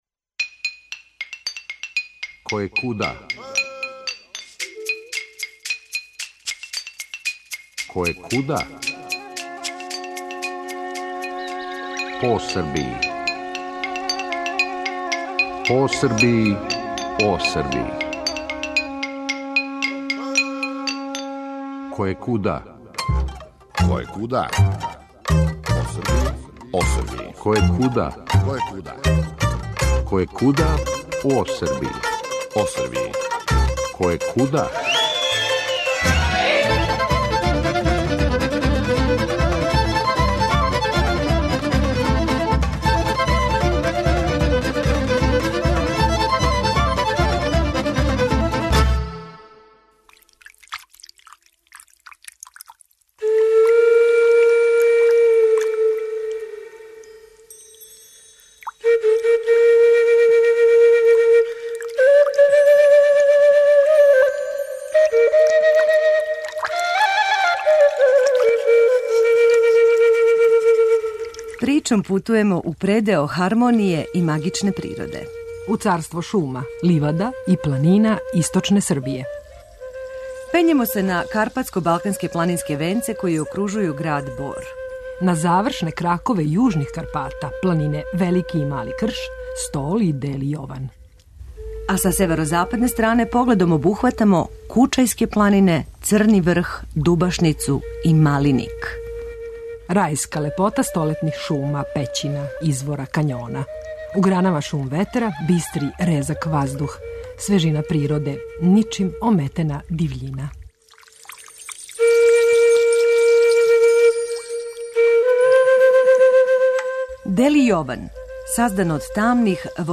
Реприза